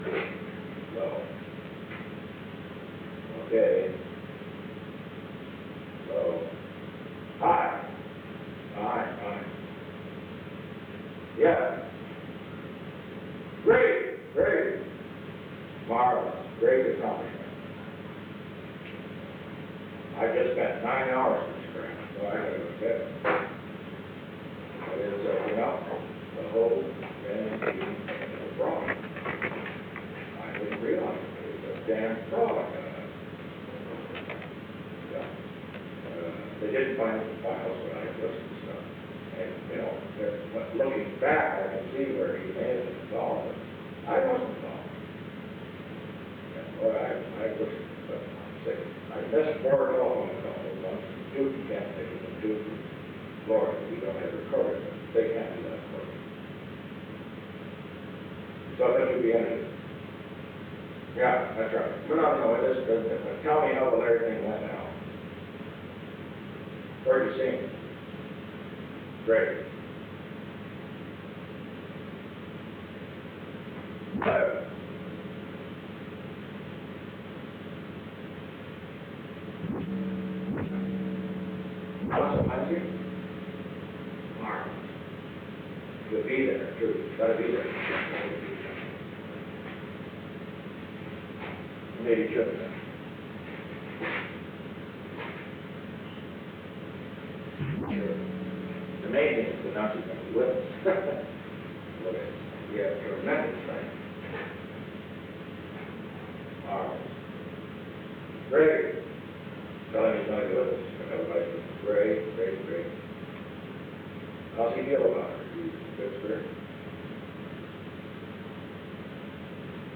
Secret White House Tapes
Conversation No. 442-67
Location: Executive Office Building
Alexander M. Haig, Jr. talked with the President.